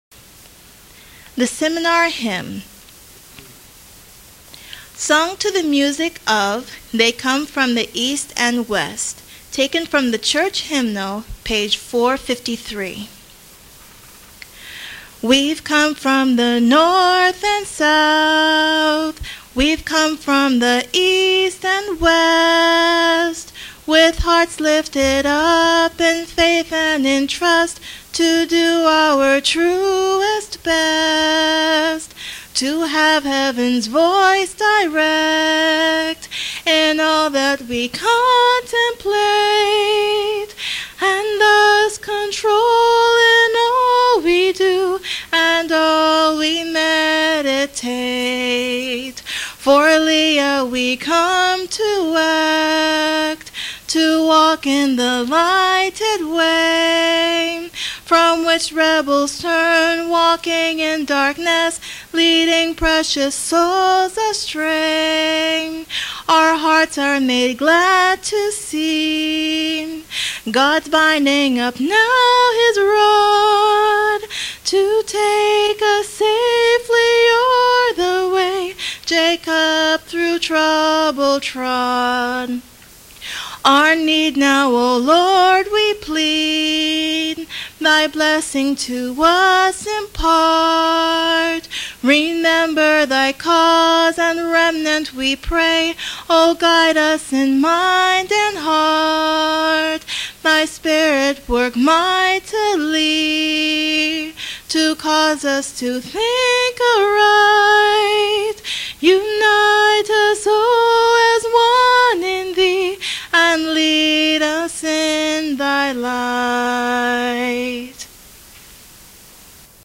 Sung without instrumental accompaniment